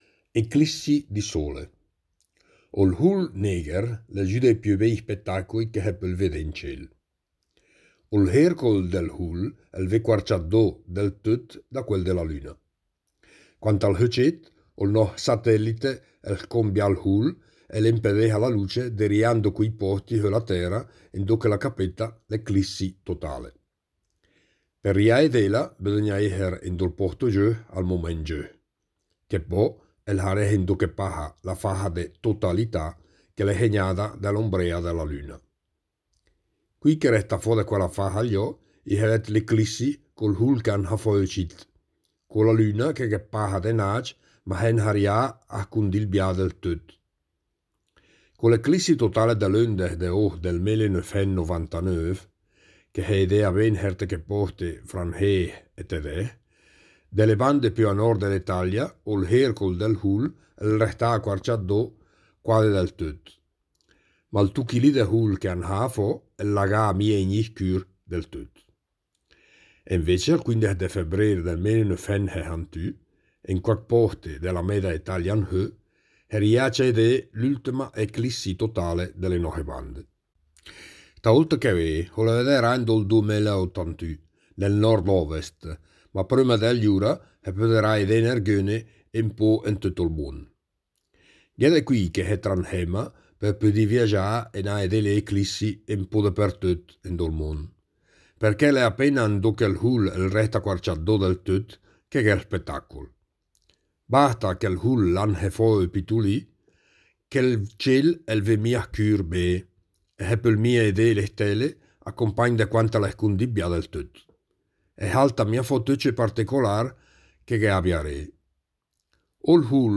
Il progetto “Idiomi celesti” promuove l’osservazione ad occhio nudo del cielo stellato con testi scritti e letti ad alta voce in lingua ladina e in dialetto lumezzanese.
15 – Eclissi di Sole (dialetto lumezzanese)
15_Eclissi_di_Sole_dialetto_lumezzanese.mp3